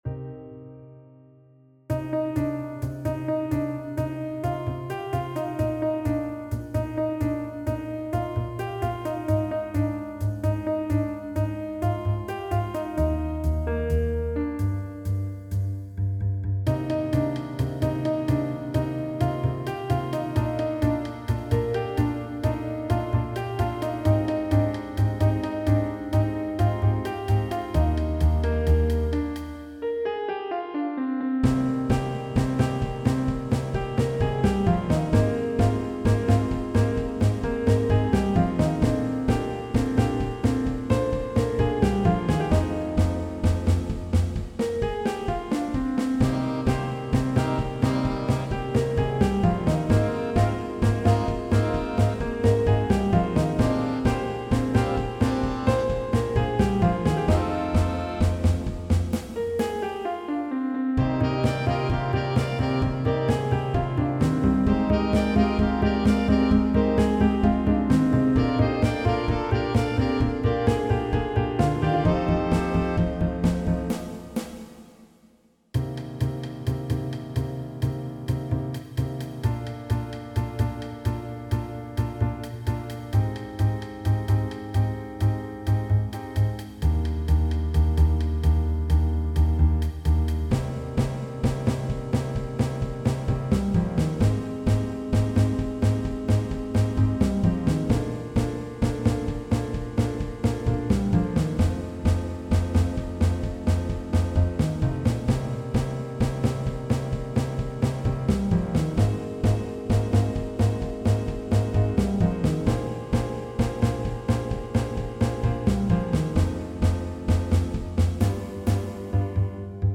Vocal, Trumpet, Sax, Trombone, Piano, Bass, Drums
All audio files are computer-generated.
(tempo = 130) with a driving feel. Vocal range Bb3 to C5.